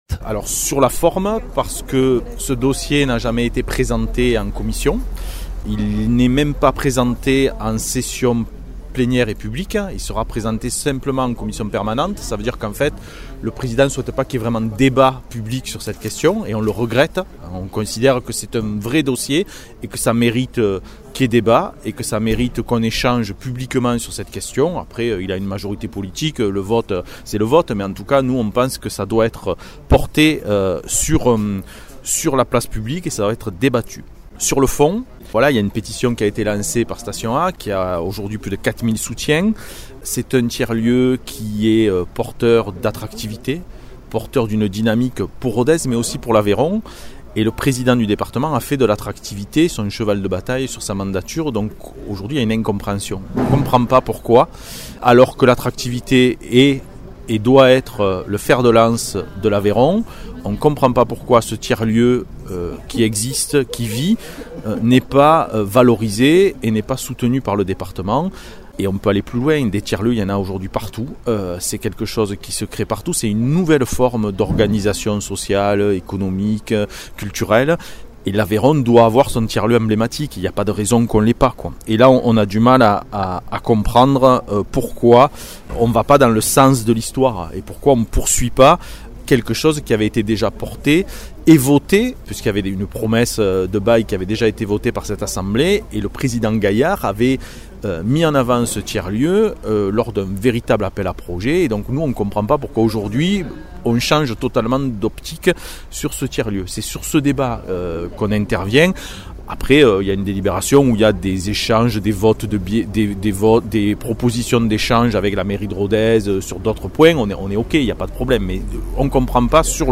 Interviews
Invité(s) : Arnaud Combet, élu départemental du groupe divers gauche ; Arnaud Viala, Président du conseil départemental